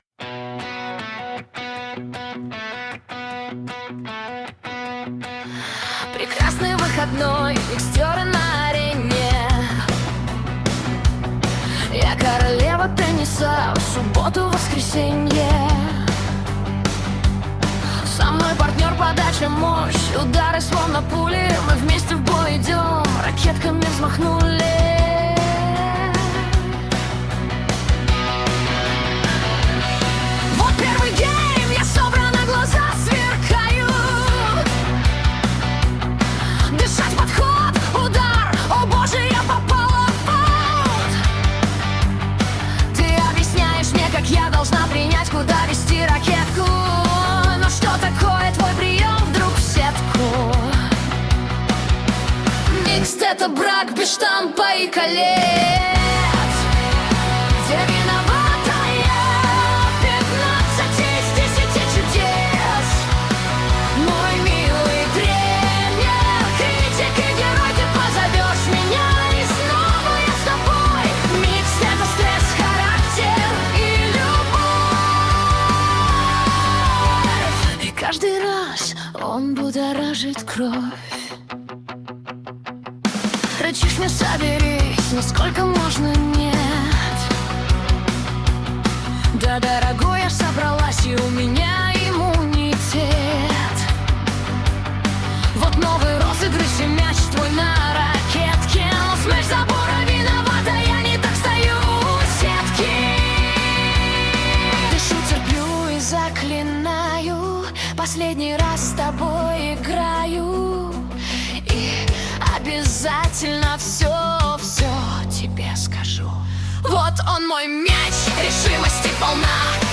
Ироничная и тёплая песня про микст в большом теннисе.